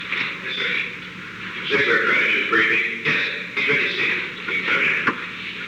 Secret White House Tapes
Conversation No. 908-14
Location: Oval Office
The President met with an unknown man.